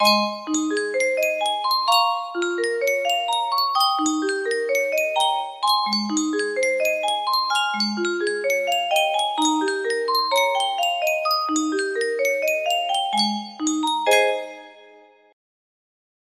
Yunsheng Music Box - Bach/Gounod Ave Maria Y232 music box melody
Full range 60